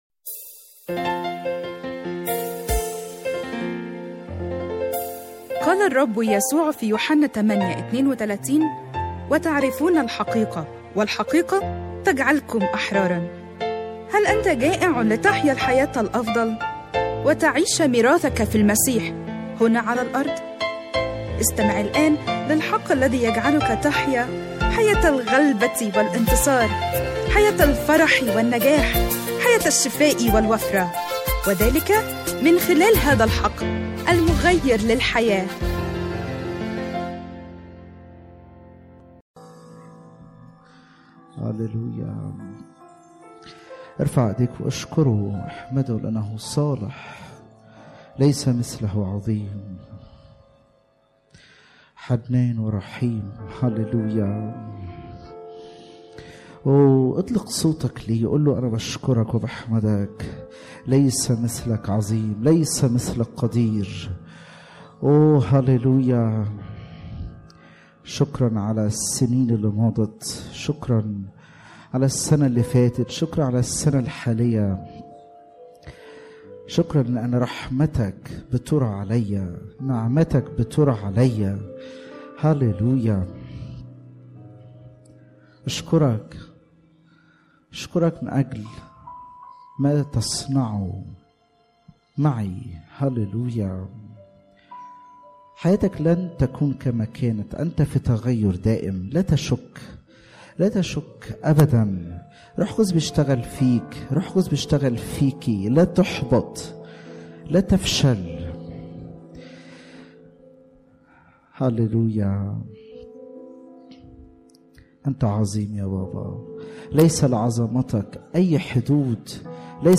🎧 Download Audio 1 تحميل عظات الثلاثاء 14/1/2020 من تأليف وإعداد وجمع خدمة الحق المغير للحياة وجميع الحقوق محفوظة.